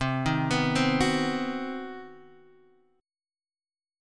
CmM7b5 chord